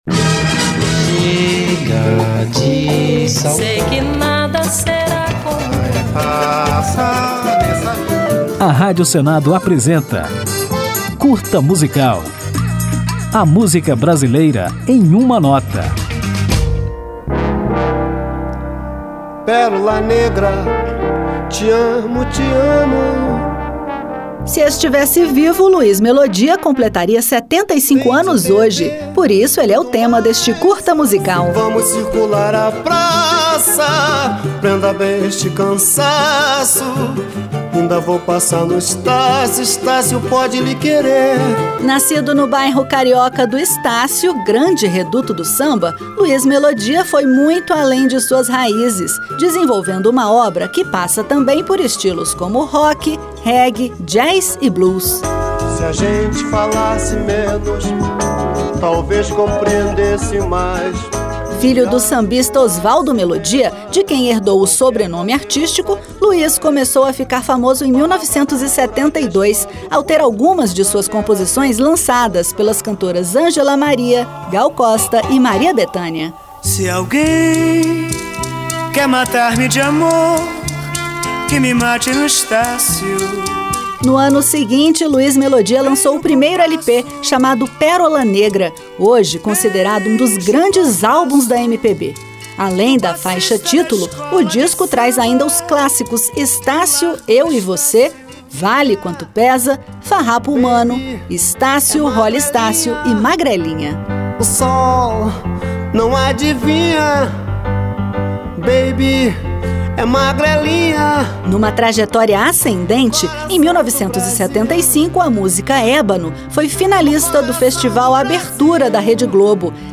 Por isso o Curta Musical preparou um especial em homenagem ao músico carioca, dono de sucessos como Estácio Holly Estácio, Vale Quanto Pesa, Magrelinha, Juventude Transviada, Congênito, Farrapo Humano, entre outros. Depois de conferir um pouco da história do artista, ouviremos Luiz Melodia com Pérola Negra, uma de suas músicas mais emblemáticas.